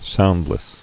[sound·less]